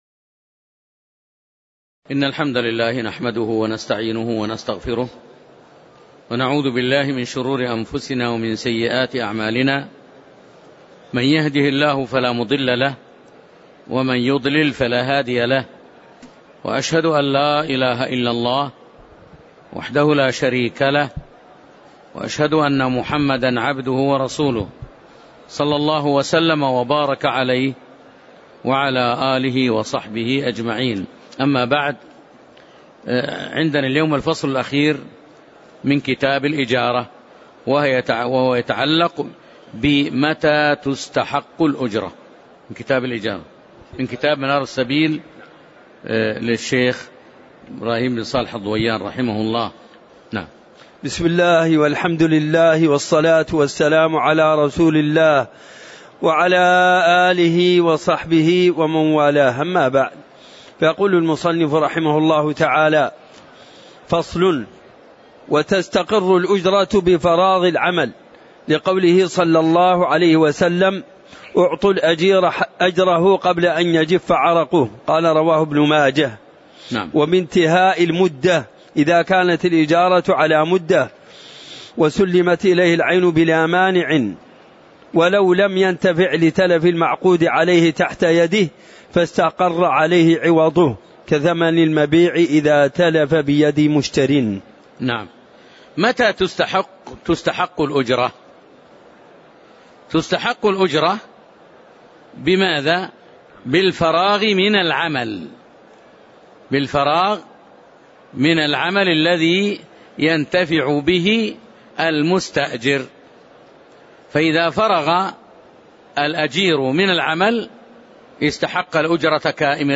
تاريخ النشر ٥ رمضان ١٤٤٣ هـ المكان: المسجد النبوي الشيخ